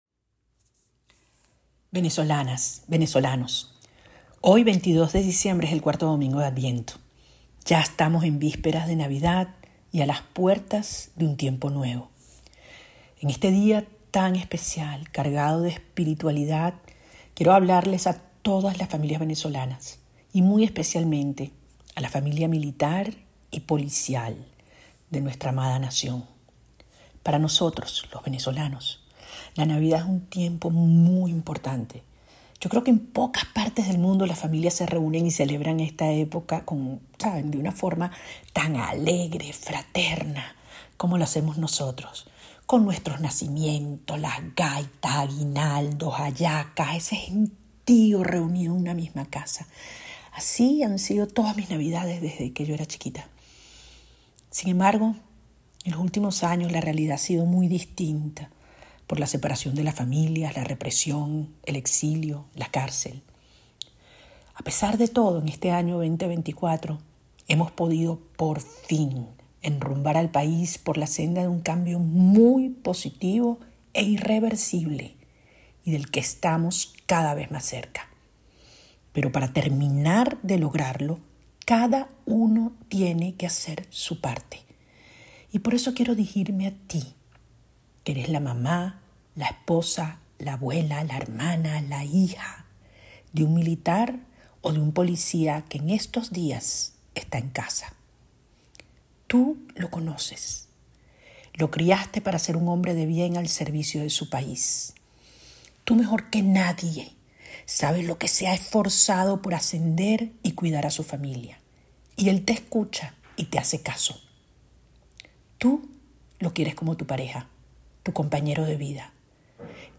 Este domingo, la líder de la oposición María Corina Machado envío un mensaje a las familias de militares y de los policías del país, a pocas horas de la celebración de Navidad.